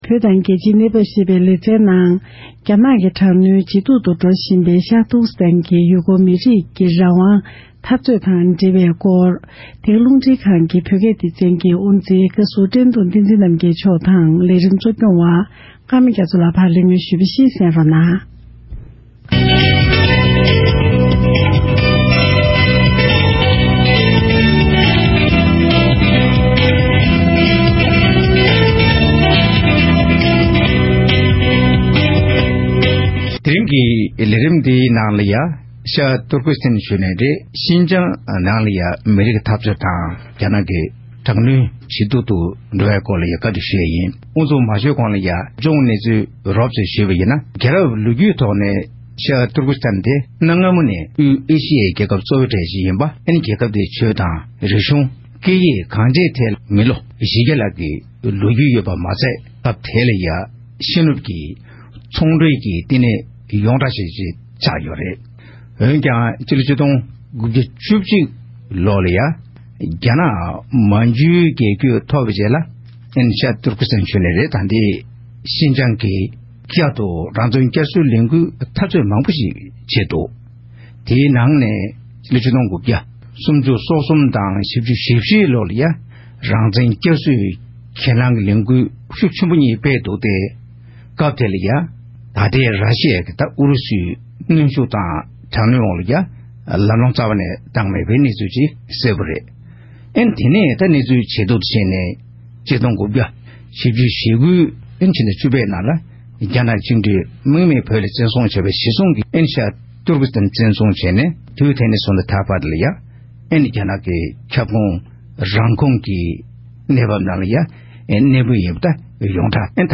དབར་གླེང་མོལ་གནང་བར་གསན་རོགས༎